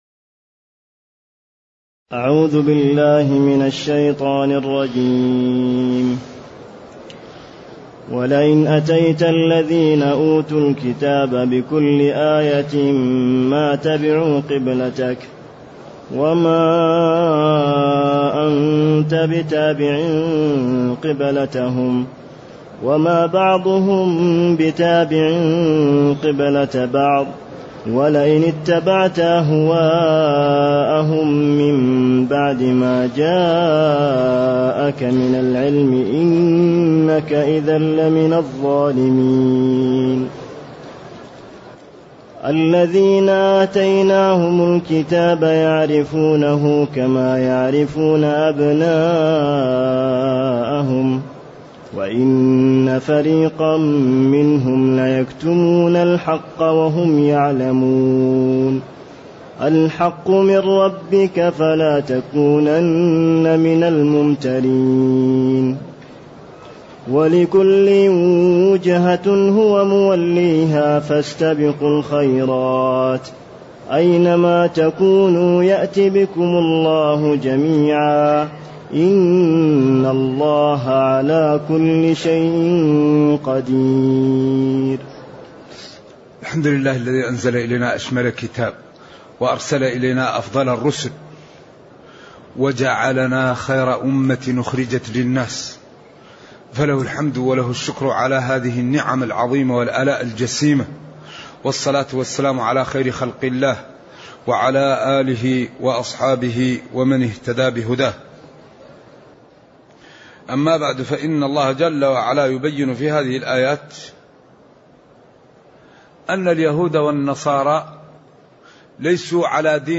تاريخ النشر ١ رجب ١٤٢٨ هـ المكان: المسجد النبوي الشيخ